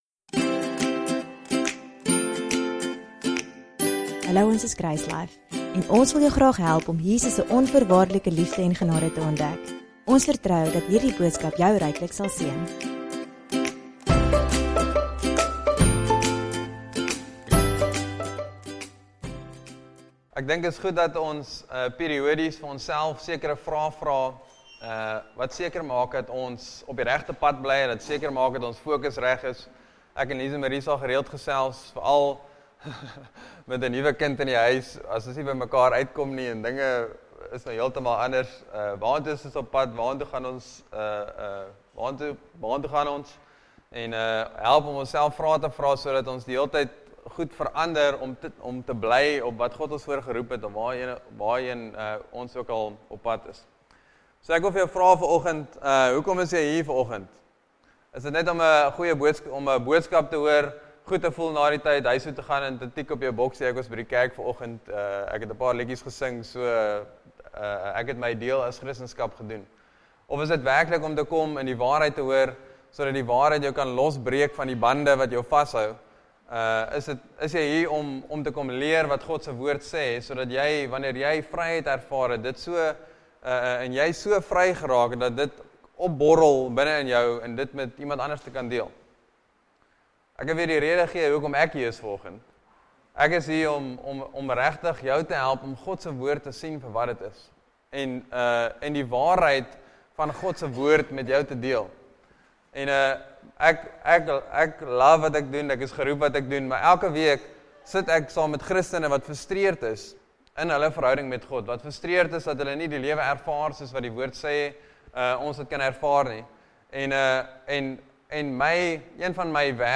DOWNLOAD READ MORE Sermon Test Category